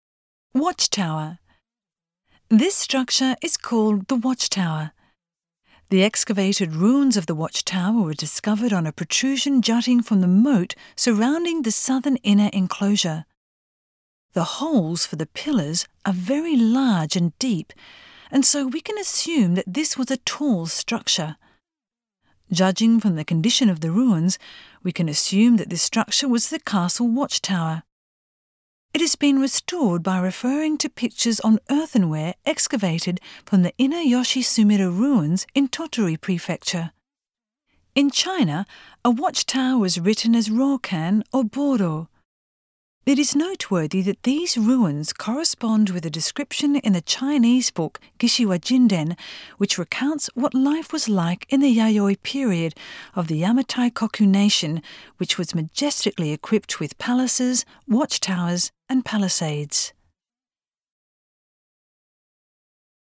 Voice guide